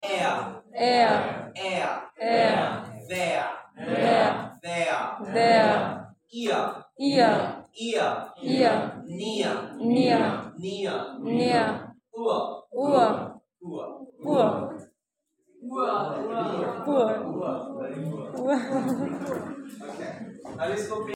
teaching english sounds.